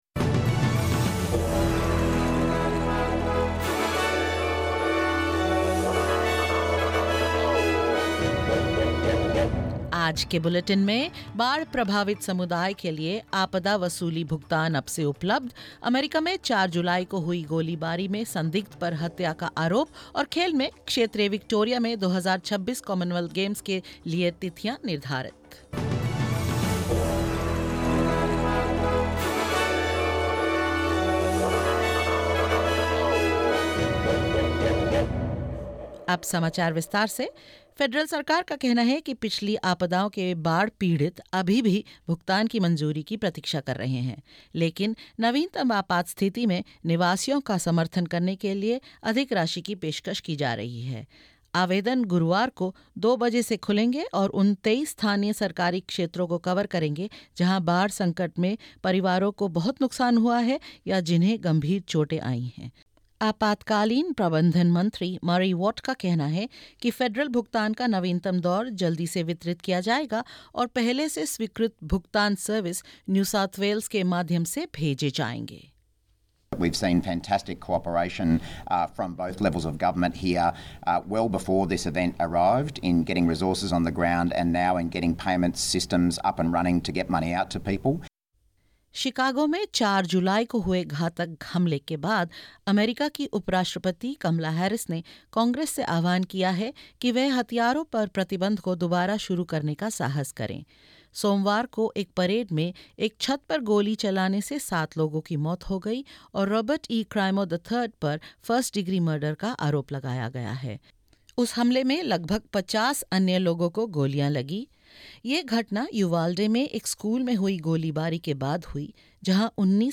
In this latest SBS Hindi bulletin: Federal government supports flood-affected communities by rolling out disaster recovery payments; Suspect in fourth of July rooftop shooting charged with murder; Dates confirmed for Commonwealth Games 2026 in regional Victoria and more.